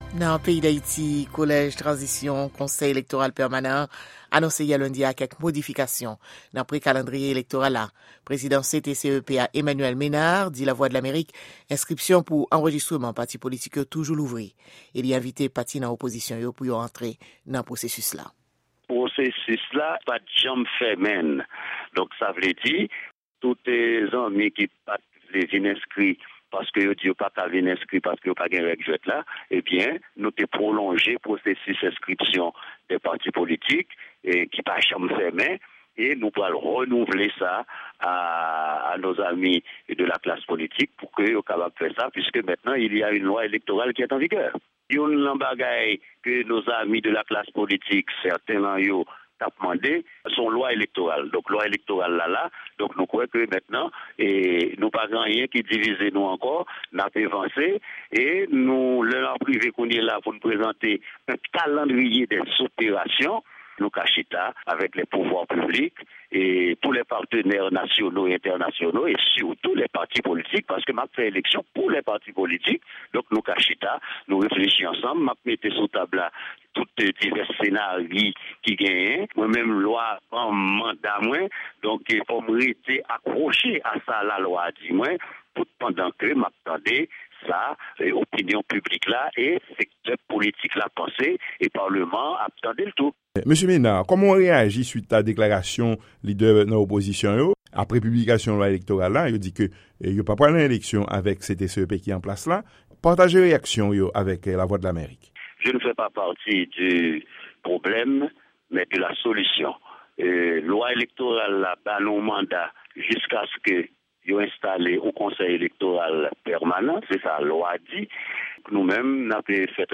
Yon repòtaj Lavwadlamerik